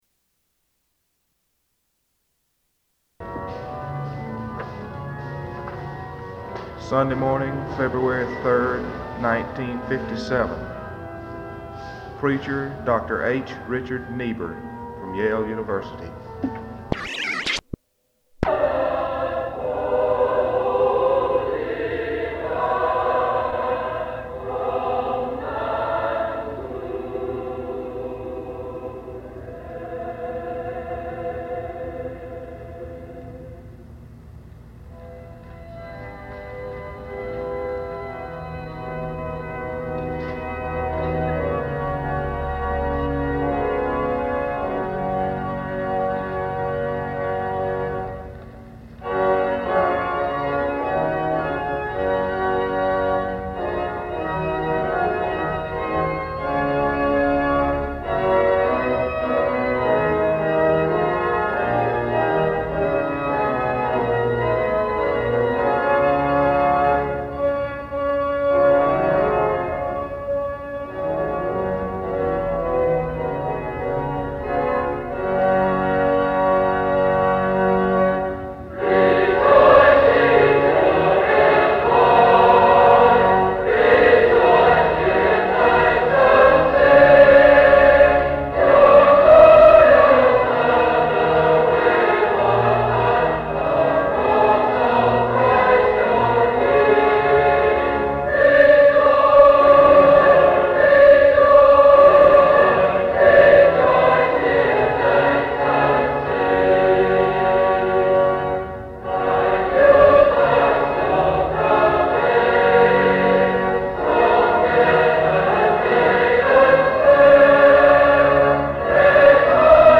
Sermon start time: (Part 1) 27:27.